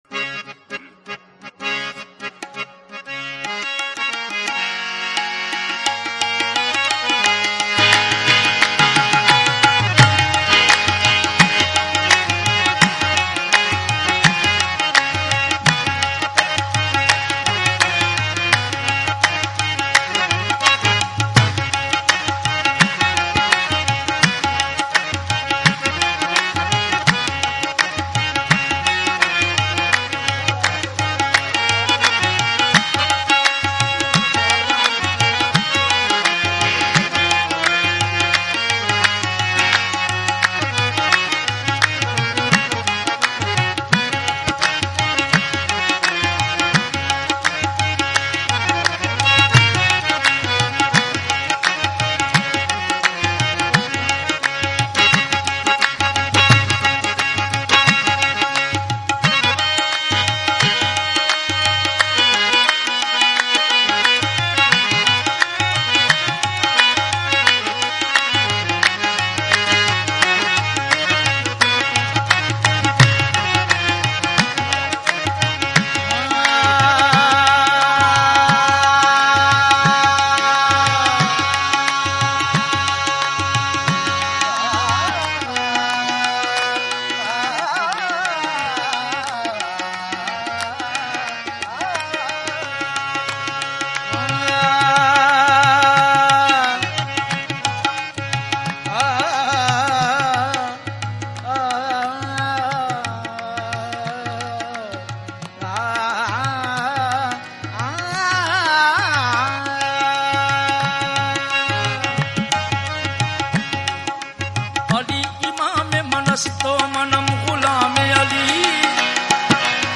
Category : Punjabi